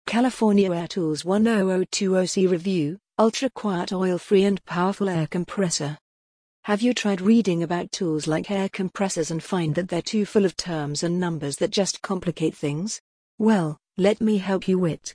Tags: air compressor compressor